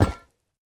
Minecraft Version Minecraft Version snapshot Latest Release | Latest Snapshot snapshot / assets / minecraft / sounds / block / vault / step3.ogg Compare With Compare With Latest Release | Latest Snapshot
step3.ogg